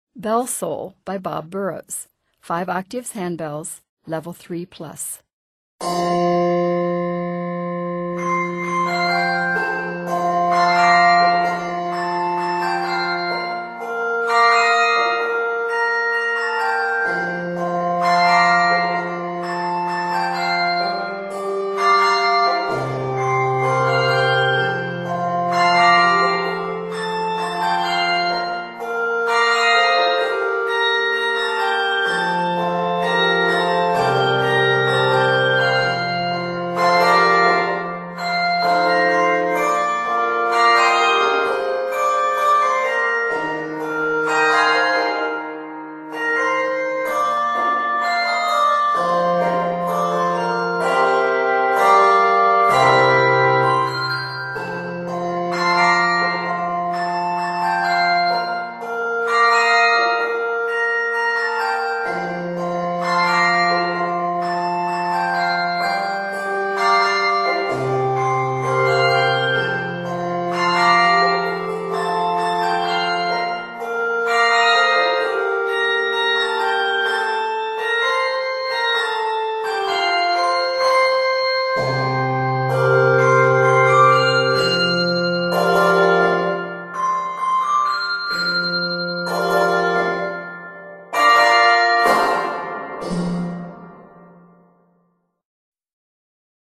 N/A Octaves: 5 Level